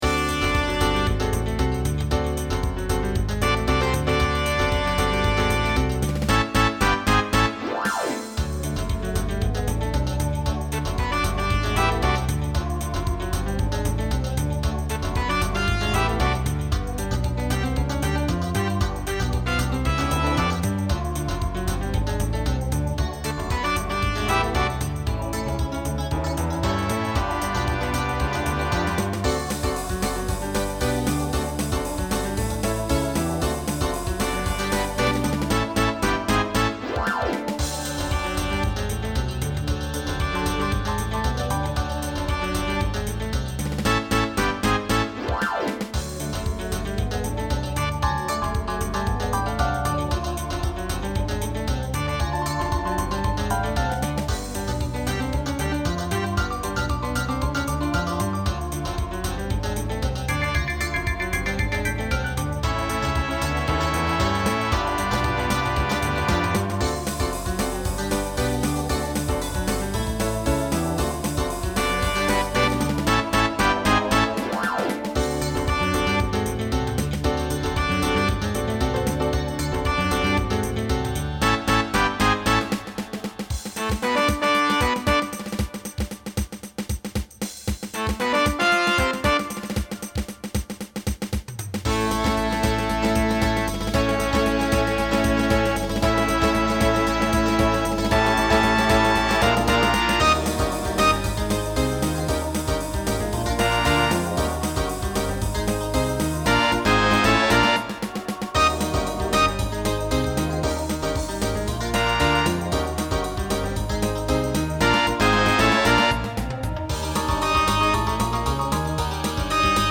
Voicing TTB
Swing/Jazz Decade 2000s Show Function Closer